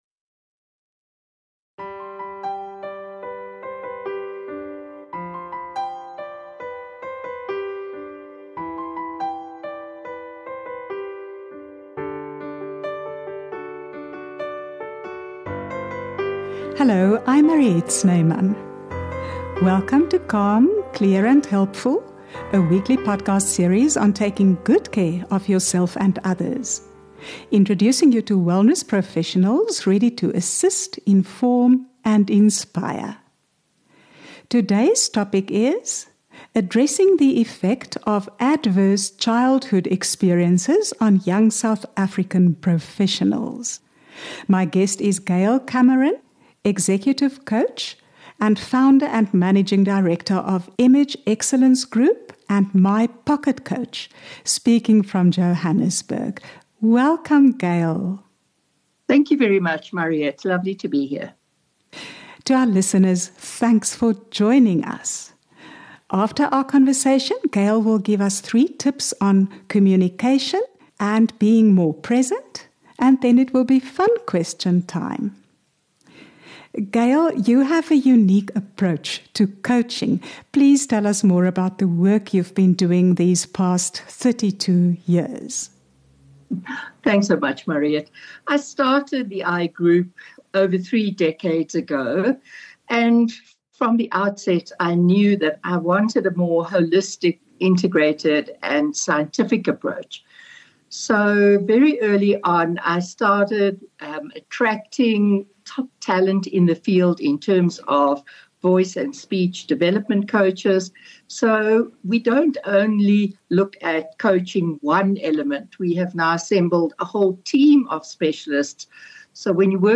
Join us each Tuesday for fresh insights, practical know-how, and for conversations from the heart.